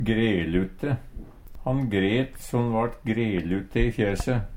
grelute - Numedalsmål (en-US)
DIALEKTORD PÅ NORMERT NORSK grelute stripete Ubunde han-/hokj.